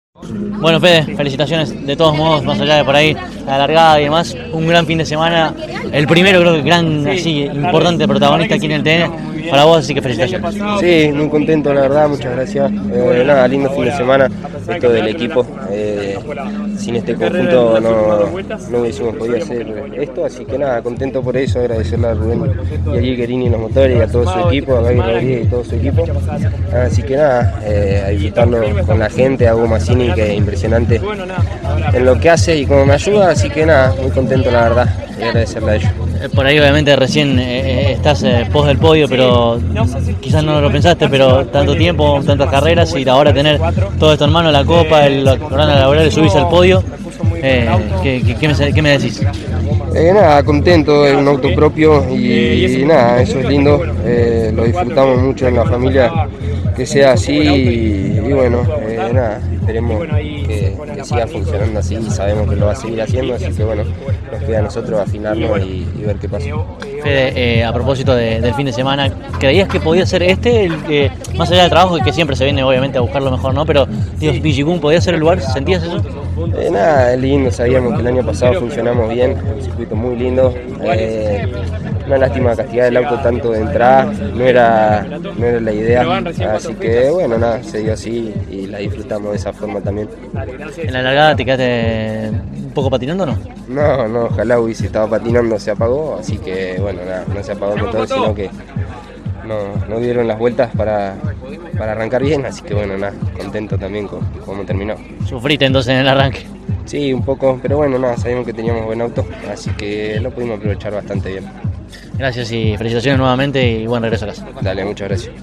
El TN disputó, este fin de semana, su cuarta cita de la presente temporada y, en el caso puntual de la divisional menor, una vez culminada la prueba final, cada uno de los integrantes del podio dialogó con CÓRDOBA COMPETICIÓN.